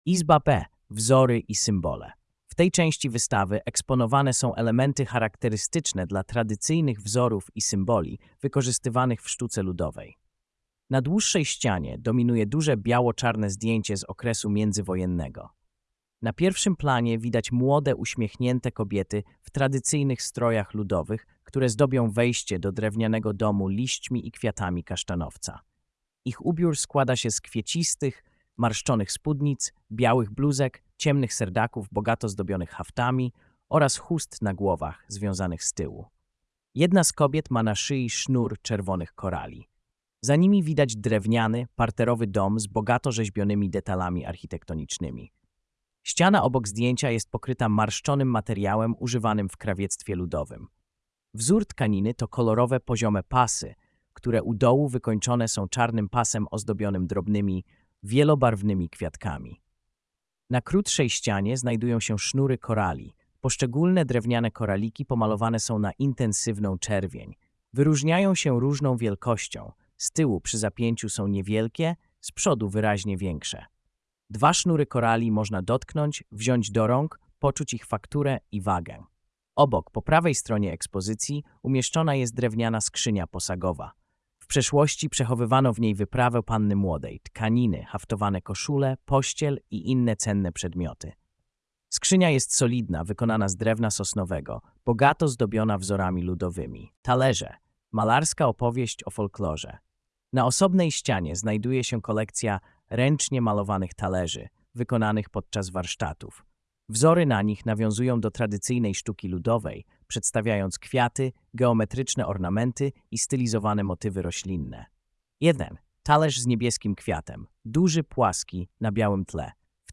Audiodeskrypcja – Wystawa Wspólny Stół - Dom Kultury "Krzemień" w Szczecinie